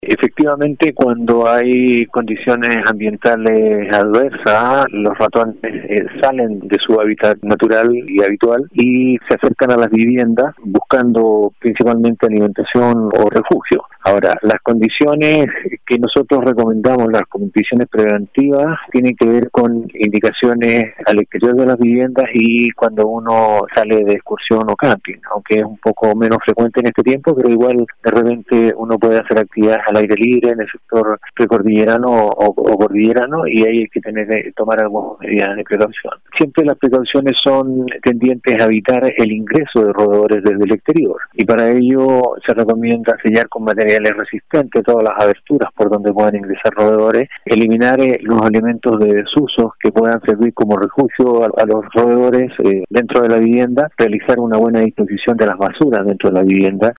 El seremi subrogante del ministerio de salud, región de Los Lagos, Raúl Bastidas, manifestó que si bien se encuentran monitoreando esta situación, por el momento no se ha reportado afectación a personas, sin embargo llamó a los habitantes de los sectores cordilleranos a adoptar medidas preventivas del caso.